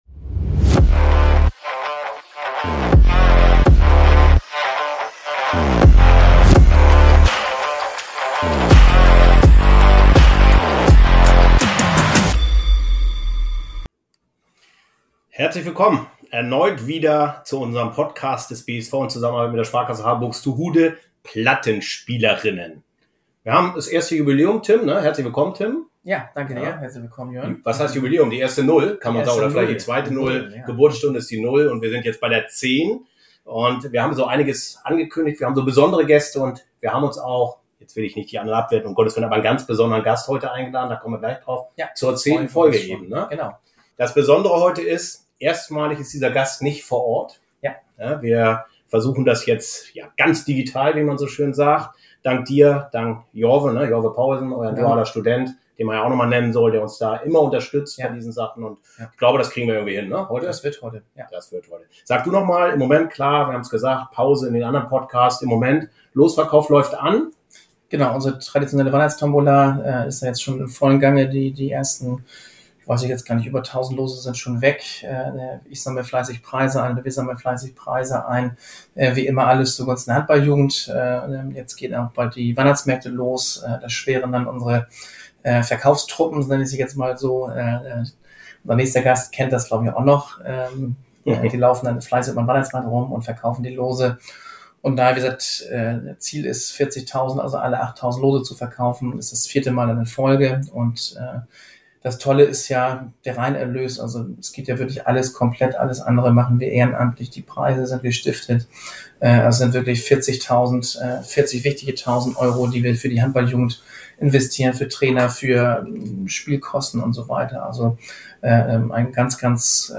In der neuen Episode von "Platte(nspielerinnen)" spricht unsere ehemalige Spielerin Emily Bölk über Olympia, die aktuelle EM und die Unterschiede im Frauenhandball von Deutschland und Ungarn.